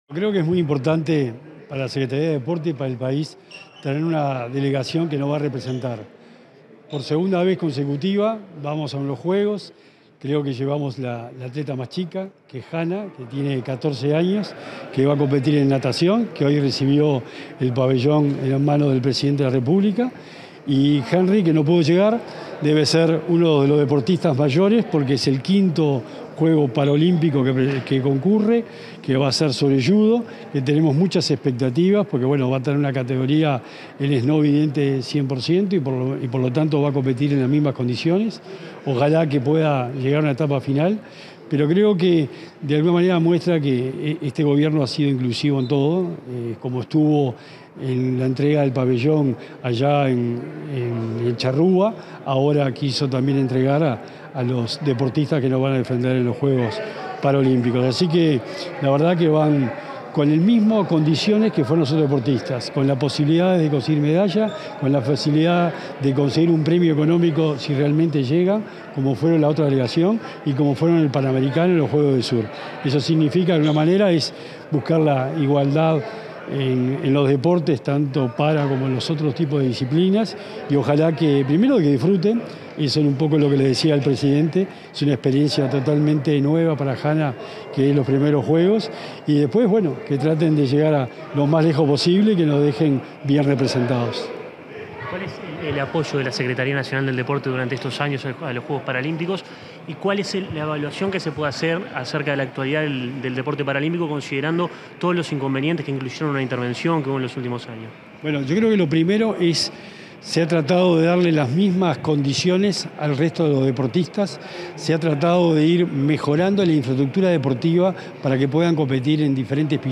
Declaraciones del secretario nacional del Deporte, Sebastián Bauzá
Declaraciones del secretario nacional del Deporte, Sebastián Bauzá 20/08/2024 Compartir Facebook X Copiar enlace WhatsApp LinkedIn Luego de asistir a la entrega del pabellón nacional a la atleta uruguaya que participará en los Juegos Paralímpicos de París, este 20 de agosto, el secretario nacional del Deportes, Sebastián Bauzá, realizó declaraciones a la prensa.